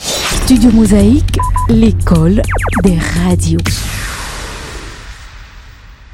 03_JINGLE_ECOLE_DES_RADIO.mp3